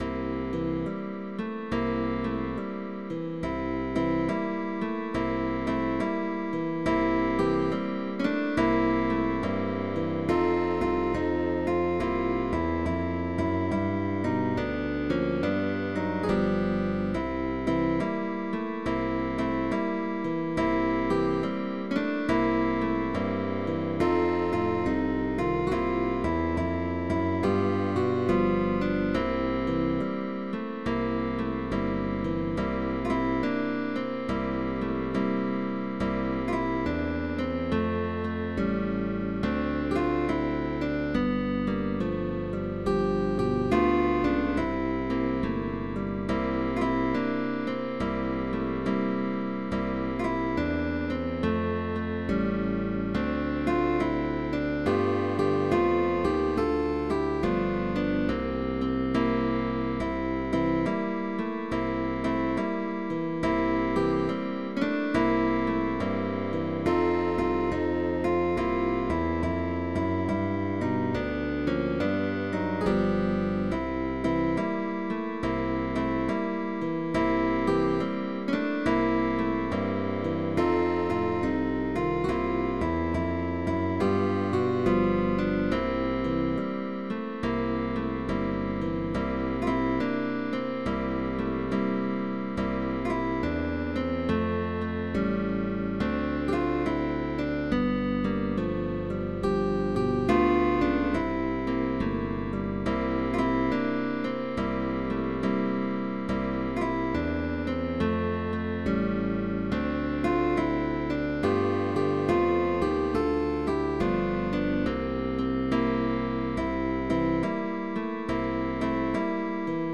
TRIO de GUITARRAS
es una versión swing con armonías de blues
Partitura para trío de guitarras.
Con bajo opcional